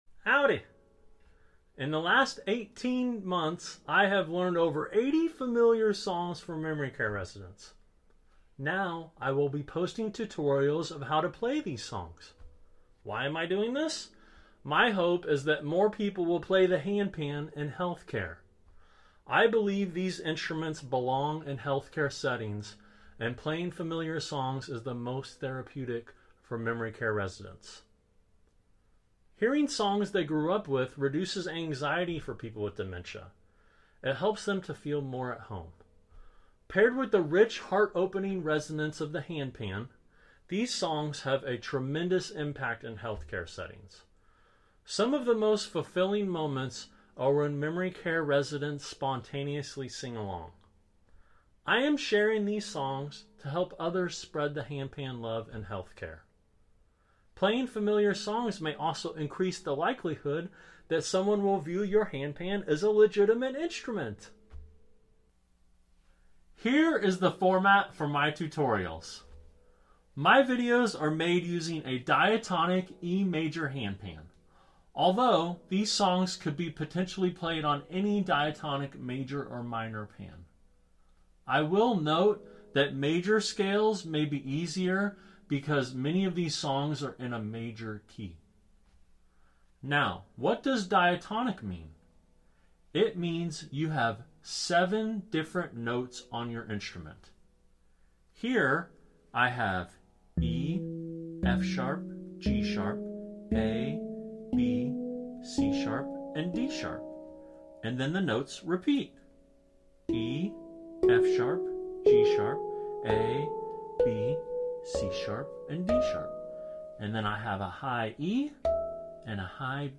🤩 The handpan has such a warm heart opening sound that touches the soul deeply.